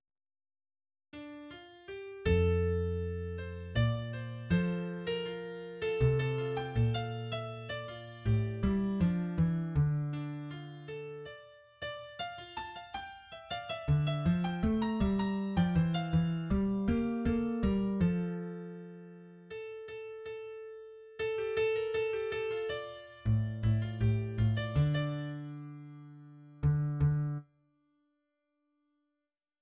3rd verse